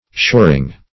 Shoring \Shor"ing\, n.